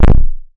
Index of /m8-backup/M8/Samples/breaks/breakcore/earthquake kicks 2
broken kick.wav